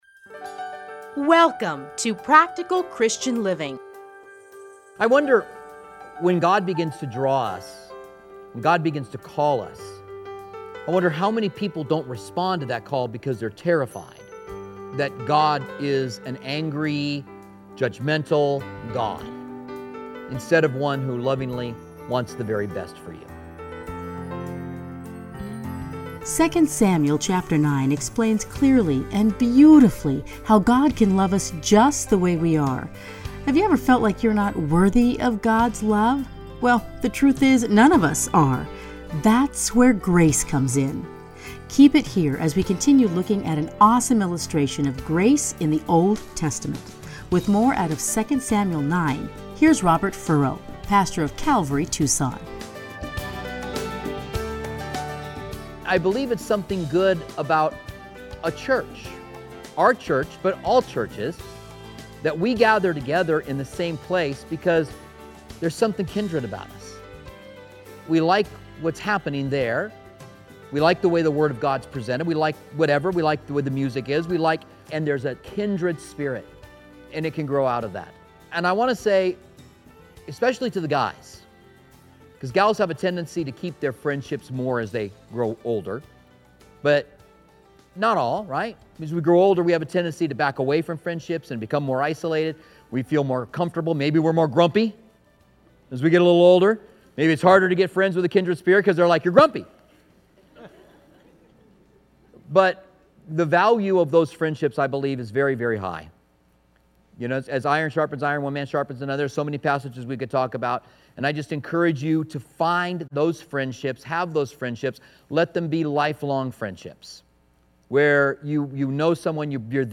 edited into 30-minute radio programs